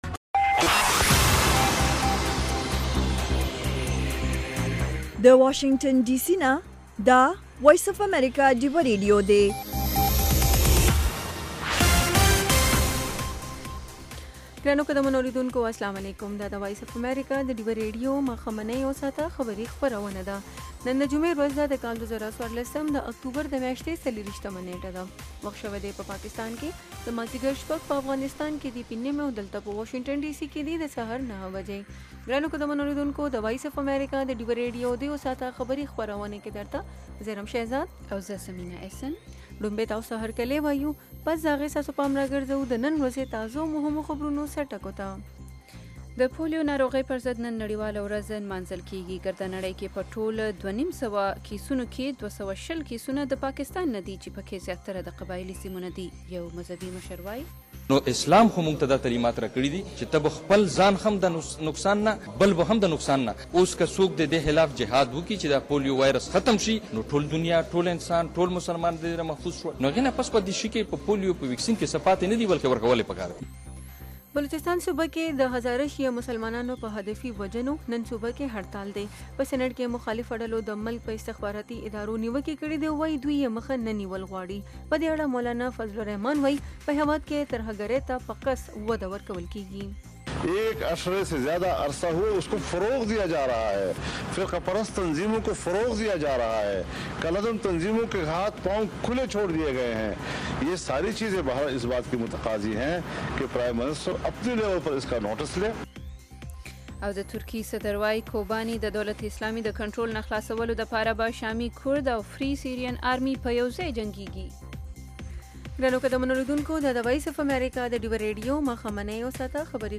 خبرونه - 1300